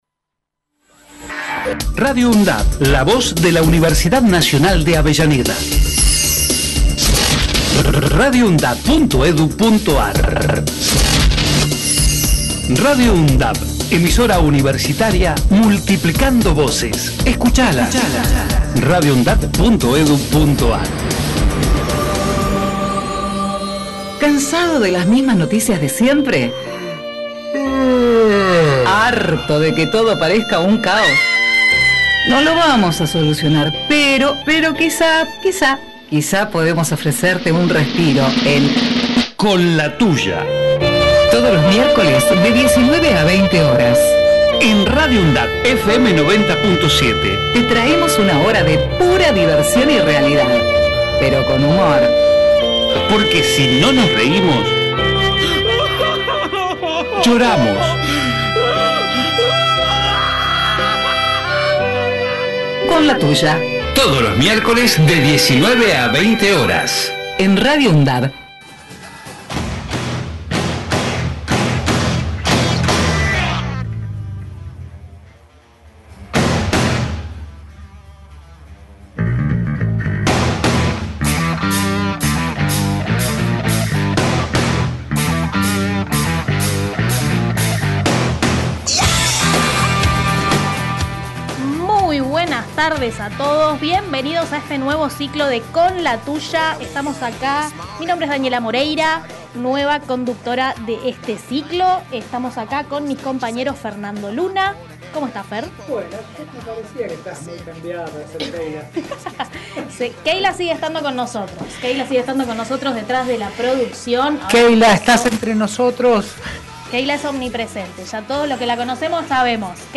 Cuenta también con una columna itinerante, con entrevistas e invitados especiales que serán parte de este programa que sale los viernes de 19 a 20.